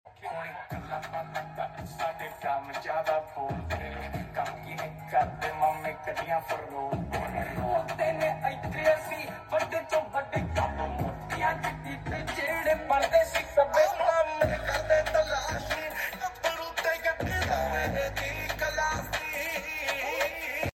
4 Channel Mixer Sound Testing!❤👍🏻 sound effects free download